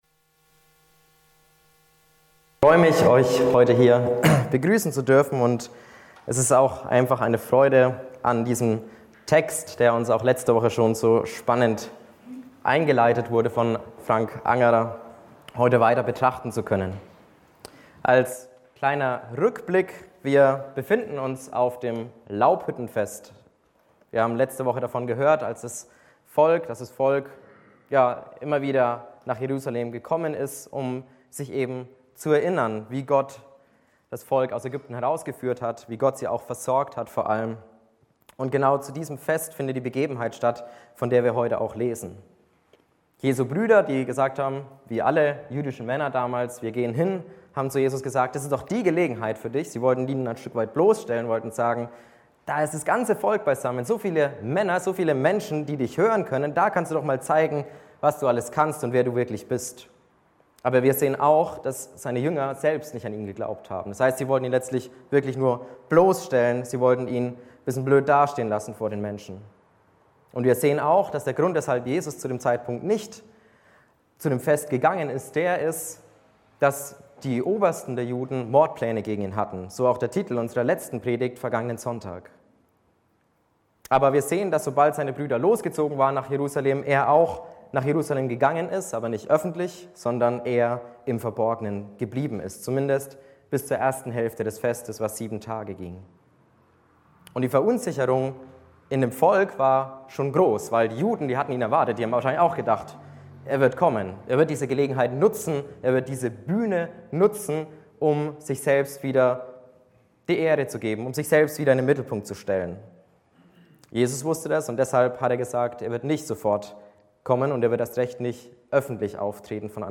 Die Predigt ist in folgende zwei Punkte gegliedert: Verunsicherung wegen Jesu Vollmacht Verunsicherung wegen Jesu Herkunft HAUSKREISLEITFADEN Aufnahme (MP3) 40 MB PDF 2 MB Zurück Ein Fest mit Morddrohungen Weiter Wachstum